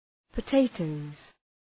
{pə’teıtəʋz}
potatoes.mp3